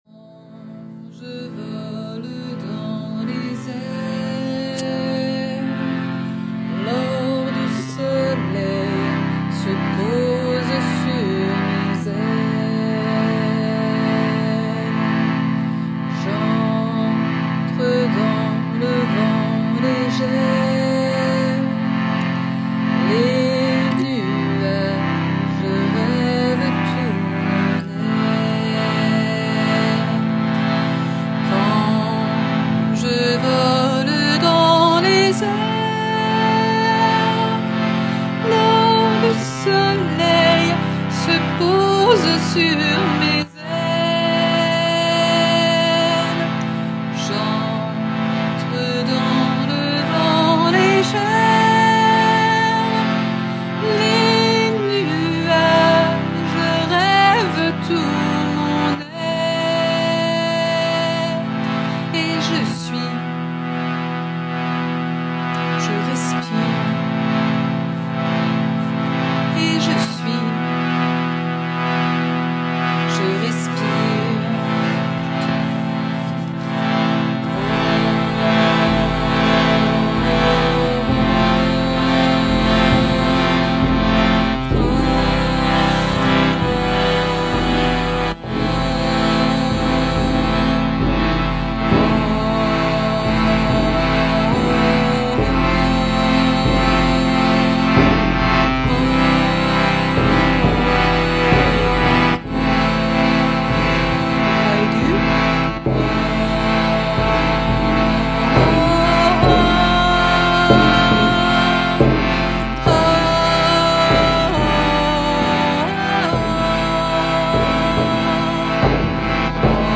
1 X Ensemble (Texte voix grave)
1 x Oh oh oh Ensemble (voix grave)
1 x Texte Ensemble (voix grave + voix alti + voix soprano)